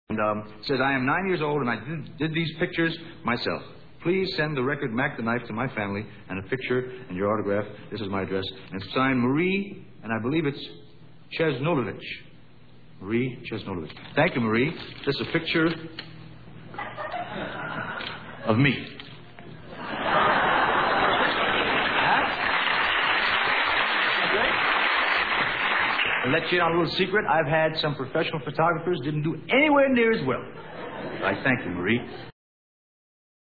Below are sound files, from his 1973 NBC varitey show, as he shares